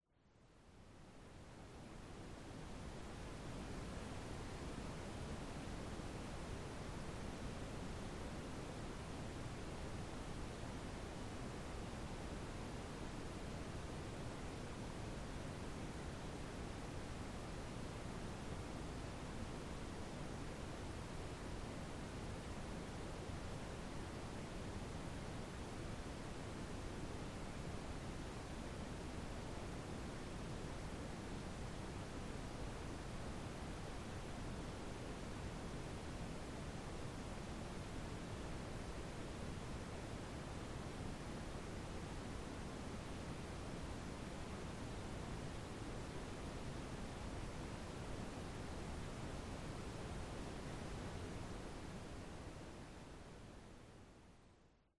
Calming nature recordings and ambient soundscapes.
Mountain Stream
Duration: 0:55 · Type: Nature Recording · 128kbps MP3
Mountain_Stream.mp3